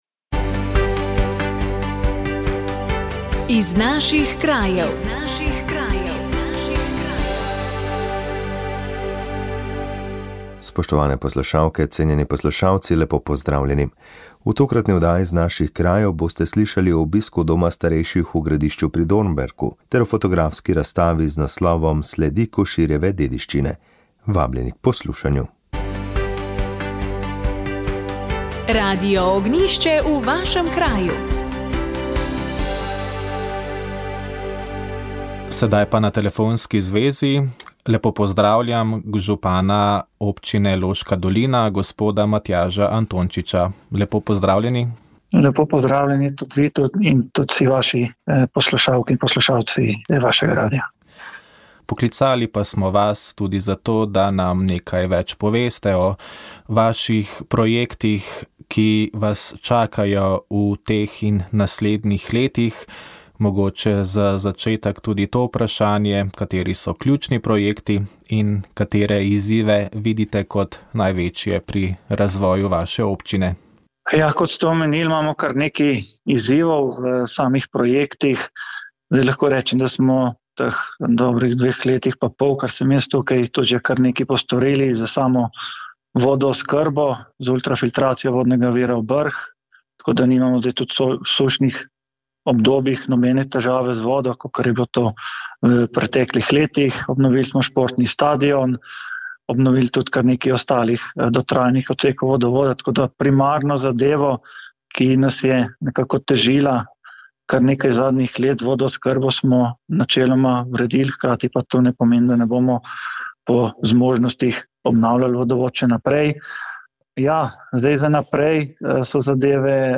Oddaja je bila posvečena dogajanju na jubileju mladih v Rimu. O tem smo govorili z mariborskim nadškofom Alojzijem Cviklom, ki je spremljal slovensko mladino.